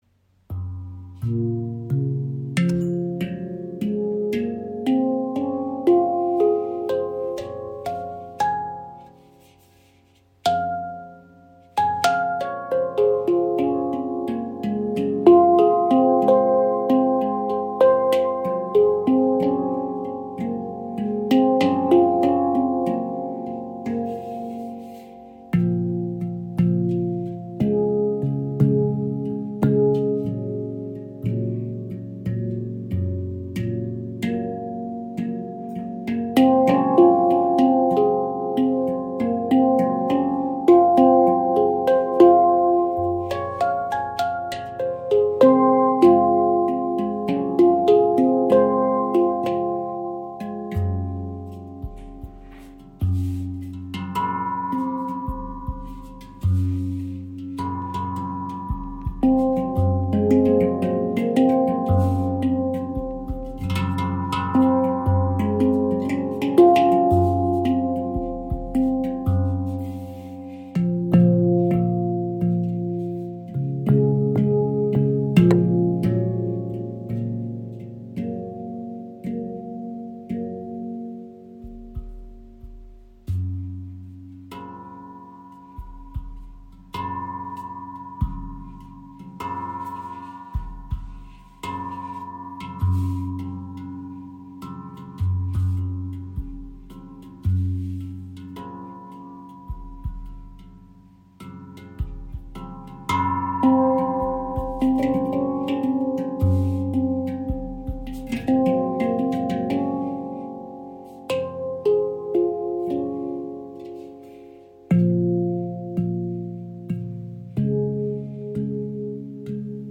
Klangbeispiel
Diese supertiefe Bass-Handpan in F# Nordlys von ShaktiPan ist aus Ember Stainless Steel und zeichnet sich durch ihre besonders lange Schwingung und schöne Klangfarbe aus.
Die F# Nordlys ist eine sehr mystische Stimmung, mit besonders schönen Harmonien. Alle Klangfelder sind sehr gut gestimmt und lassen sich auch mit fortgeschrittenen Spieltechniken anspielen.